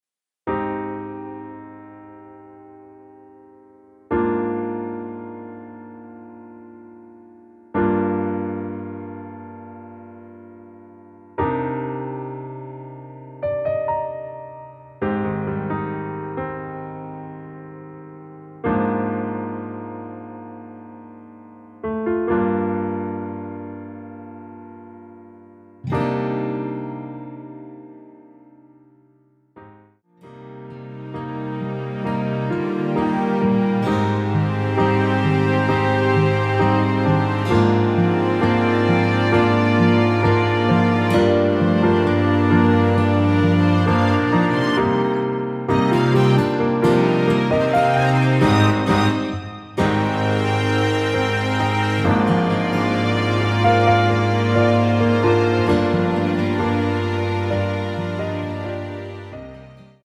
Cm
◈ 곡명 옆 (-1)은 반음 내림, (+1)은 반음 올림 입니다.
앞부분30초, 뒷부분30초씩 편집해서 올려 드리고 있습니다.